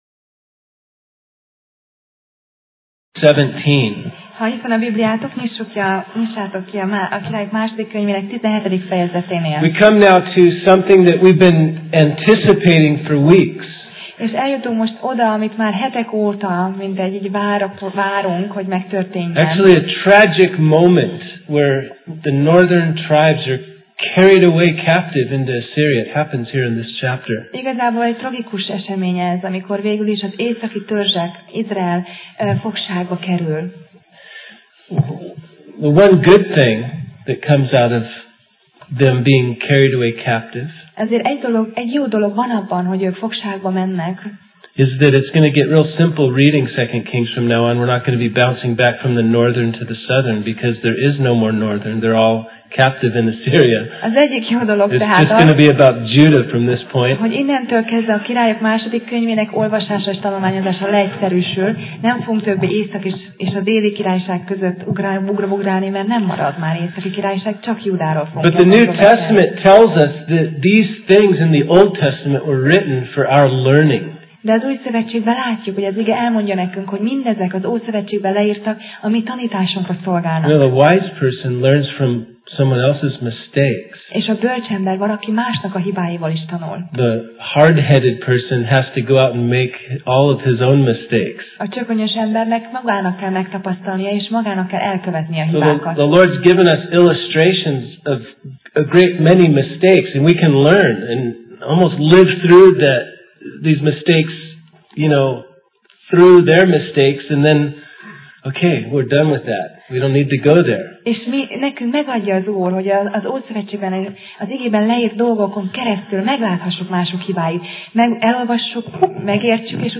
2Királyok Passage: 2Királyok (2Kings) 17 Alkalom: Szerda Este